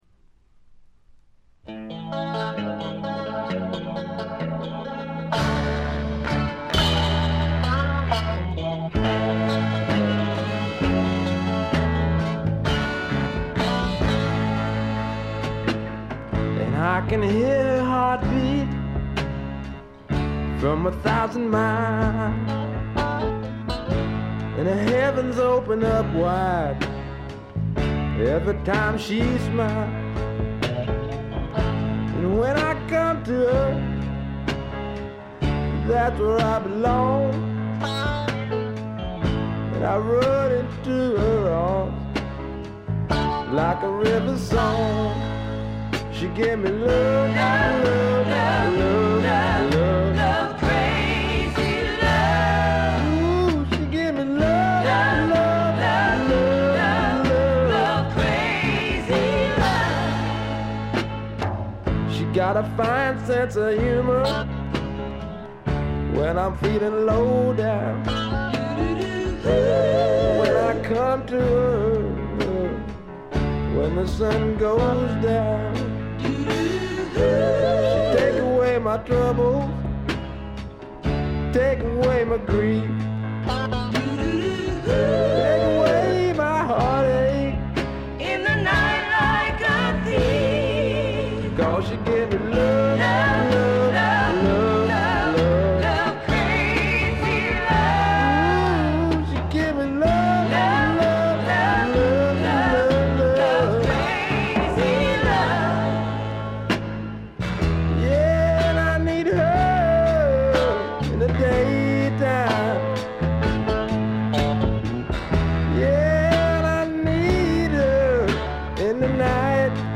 わずかに軽微なチリプチ。
まさしくスワンプロックの真骨頂。
試聴曲は現品からの取り込み音源です。
Vocal, Guitar, Keyboards